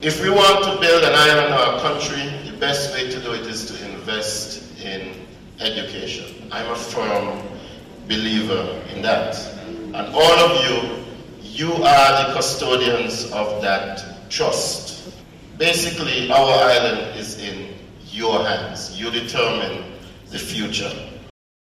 Those were remarks by Nevis’ Minister of Education, the Hon. Troy Liburd, at the annual Back to School Convocation where teachers, staff and other education officials launched the 2025-2026 Academic School Year.